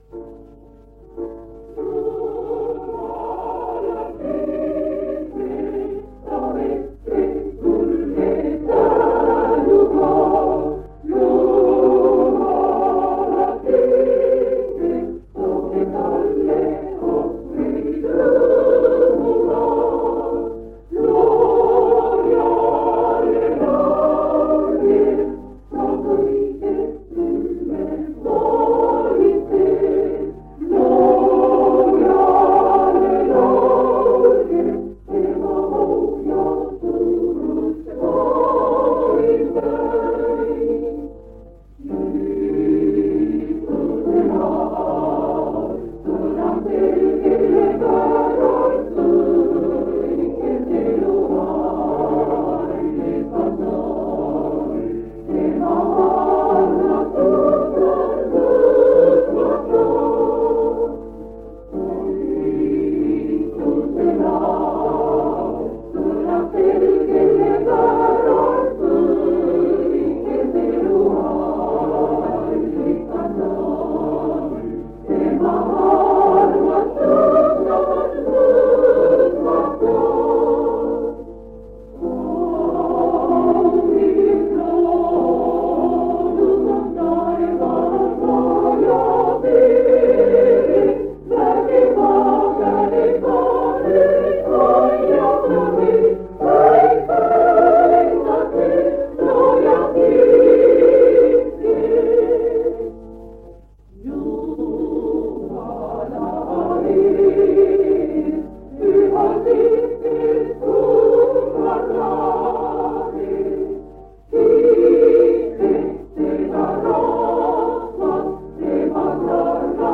Järgneva jutluse kõnelejat vanal lintmaki lindil kirjas ei ole.
Aasta on 1978 sügis, kui kartulid on juba üles võetud... Enne ja peale jutlust kuuleme ka laulukooride laulmisi.